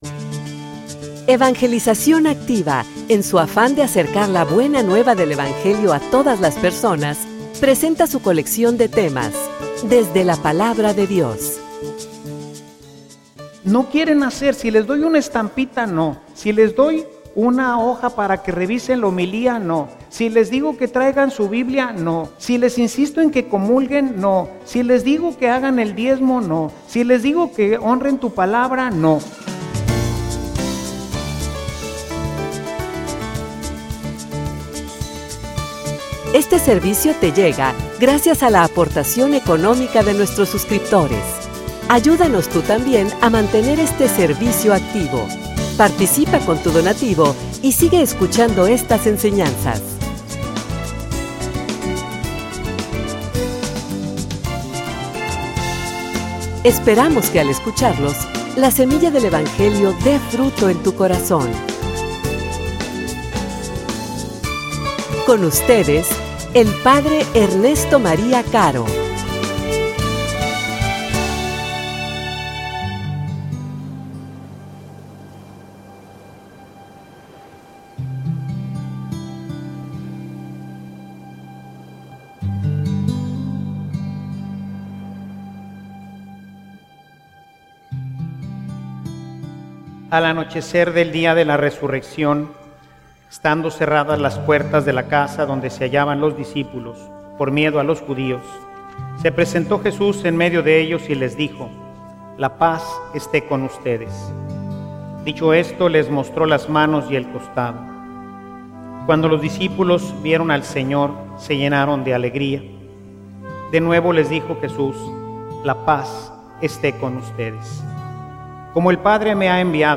homilia_Enviame_a_mi.mp3